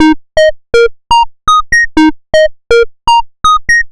Bleep Riff_122_Eb.wav